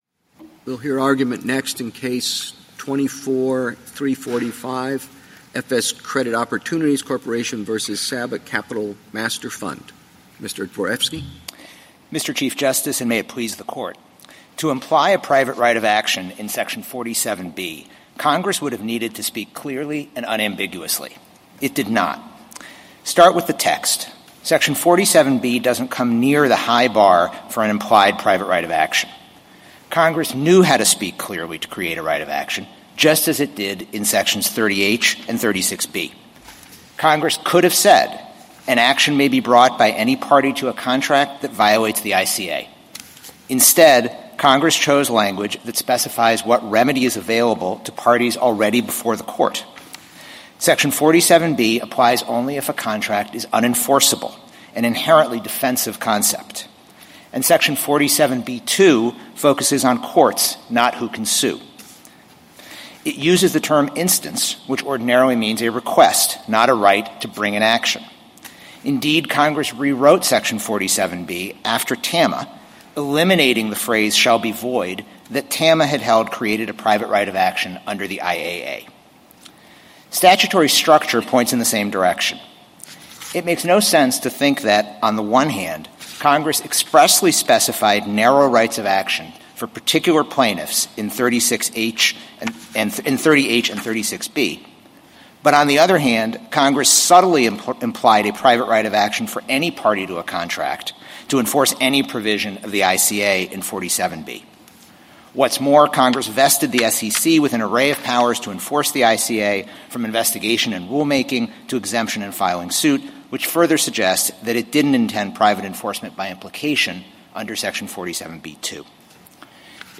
Oral Argument - Audio